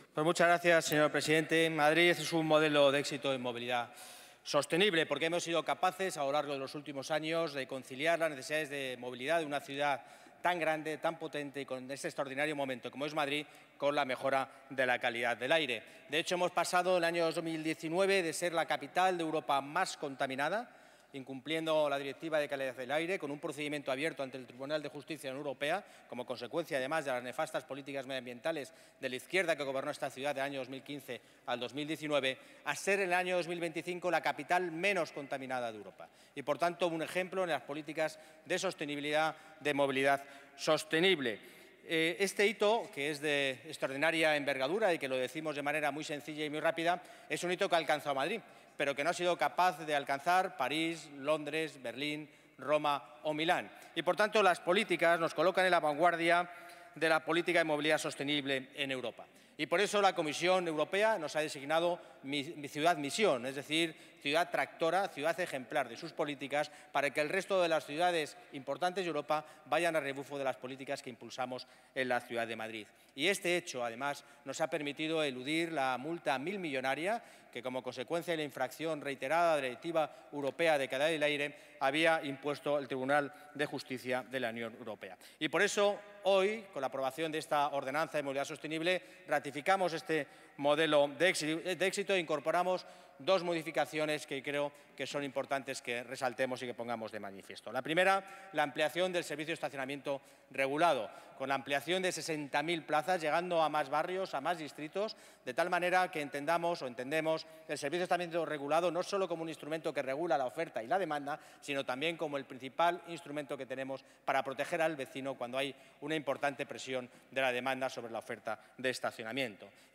El delegado de Urbanismo, Medio Ambiente y Movilidad, Borja Carabante, ha defendido en su intervención que la capital lleva cuatro años consecutivos —desde 2022— cumpliendo con la directiva europea de calidad del aire gracias a todas las actuaciones que se han implementado de Madrid 360, una estrategia que se sustenta, en gran medida, en el marco legal que establece la OMS, sobre todo para el funcionamiento de sus tres zonas de bajas emisiones: Madrid ZBE, Plaza Elíptica y Distrito Centro.